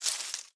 leaves6.ogg